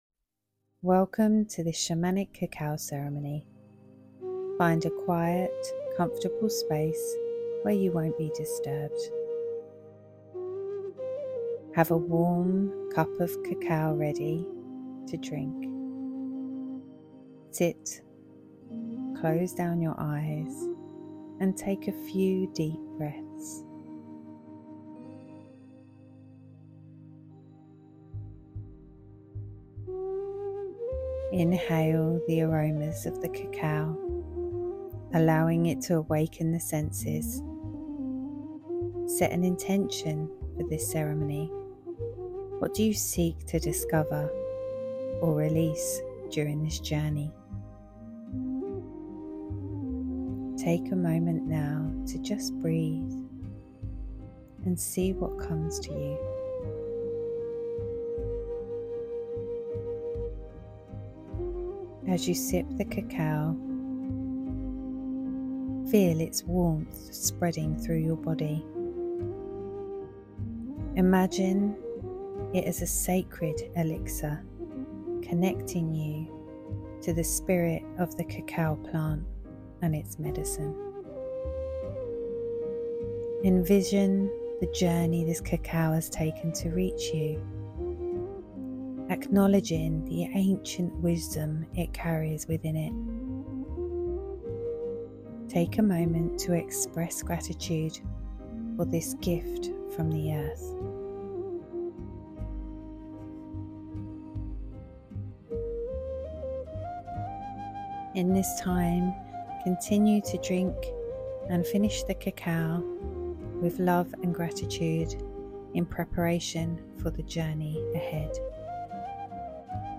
🌿2026 Shamanic Cacao Ceremony Meditation